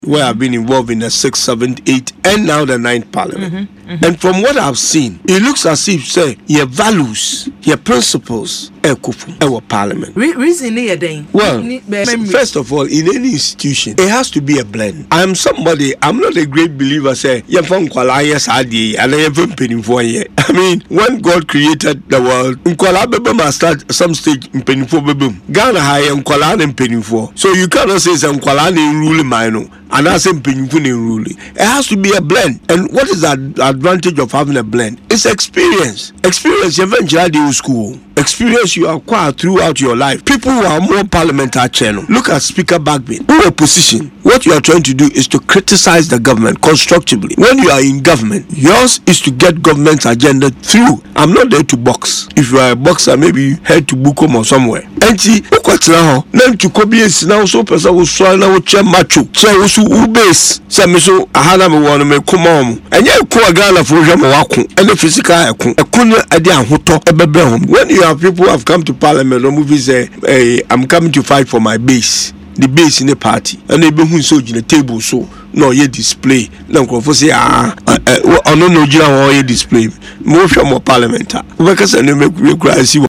Speaking on Adom FM’s Burning Issues, he attributed the deterioration to ongoing clashes between the Minority and Majority caucuses in both the 8th and 9th Parliaments, which have caused disruptions.